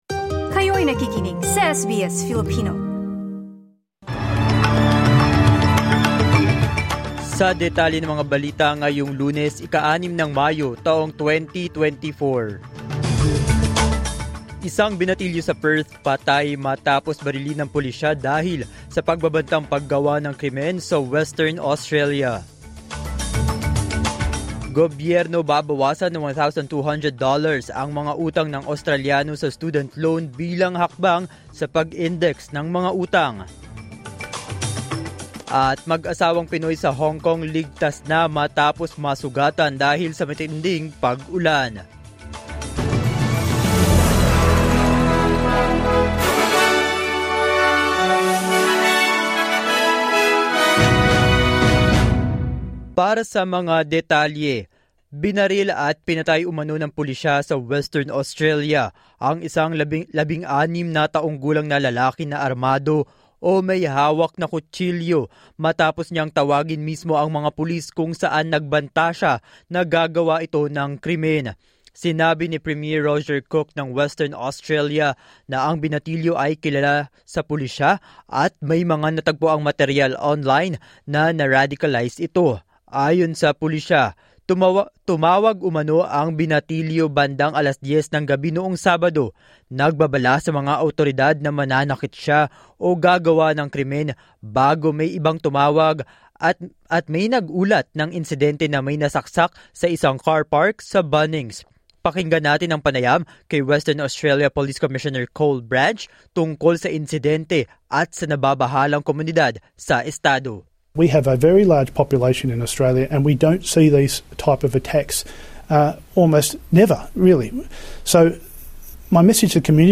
SBS News in Filipino, Monday 6 May 2024